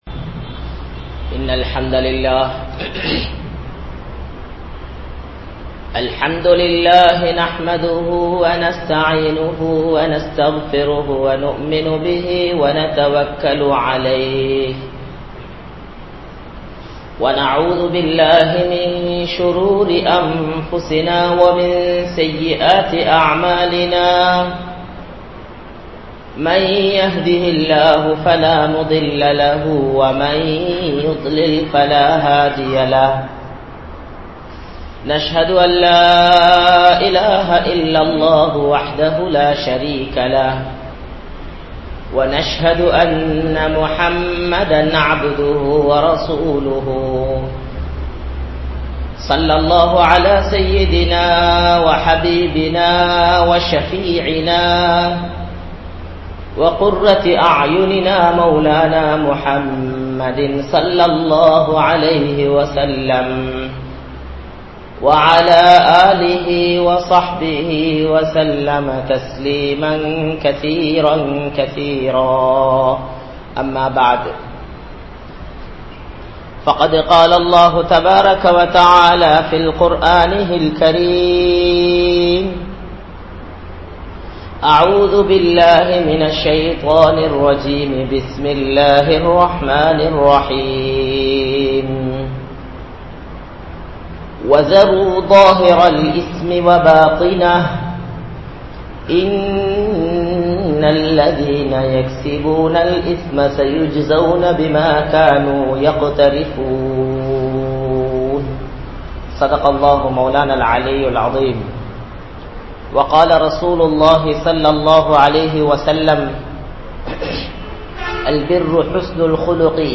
Allah`vukku Maaru Seiyaatheerhal (அல்லாஹ்வுக்கு மாறு செய்யாதீர்கள்) | Audio Bayans | All Ceylon Muslim Youth Community | Addalaichenai